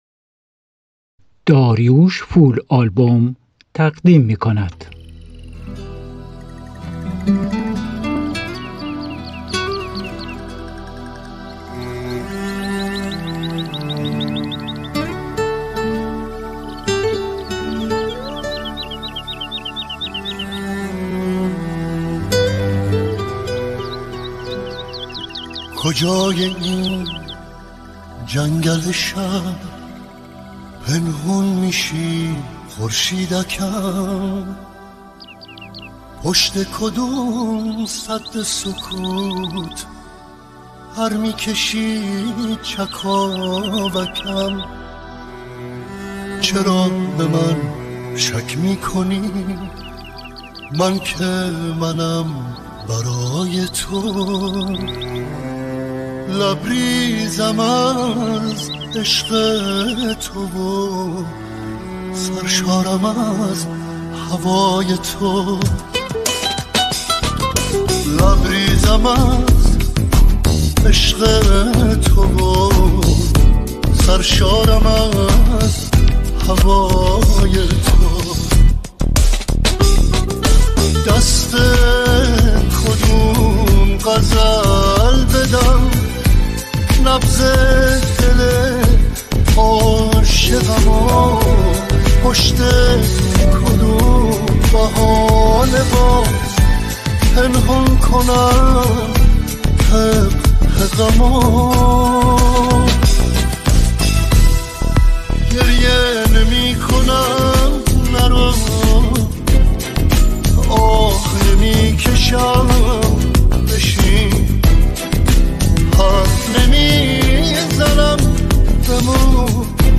ریمیکس عاشقانه ، ریمیکس غمگین